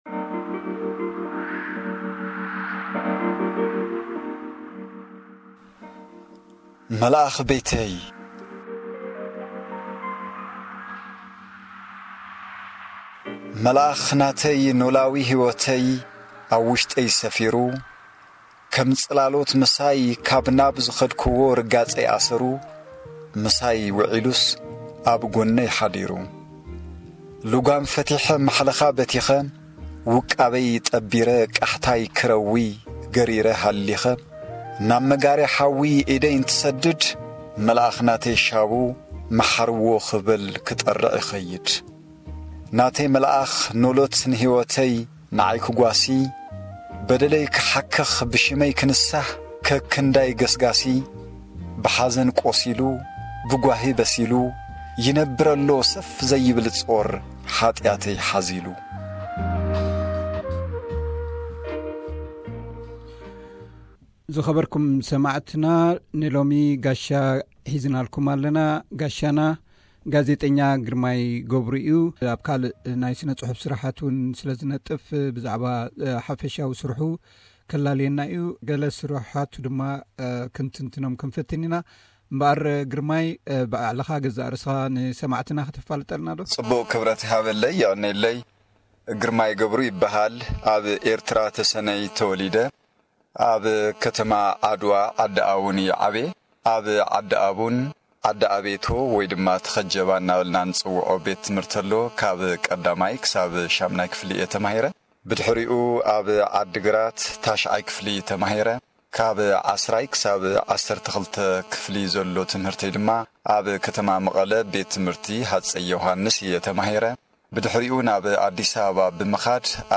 ዕላል